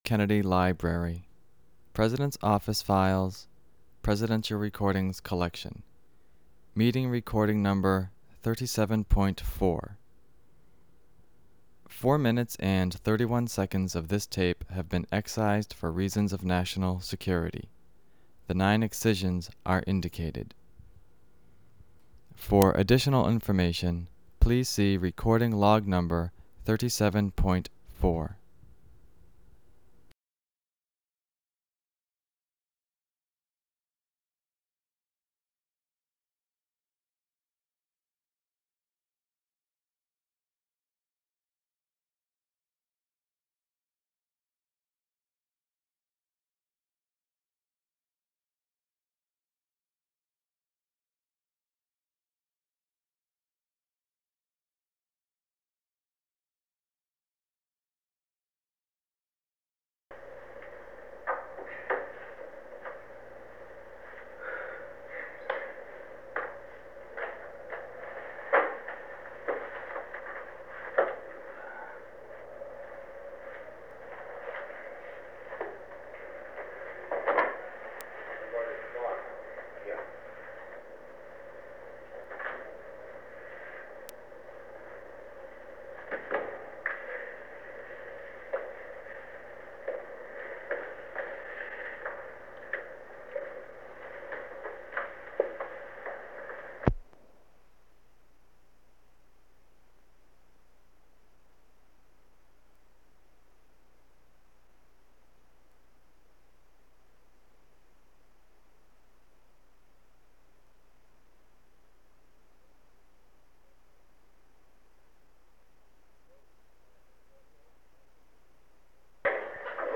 Secret White House Tapes | John F. Kennedy Presidency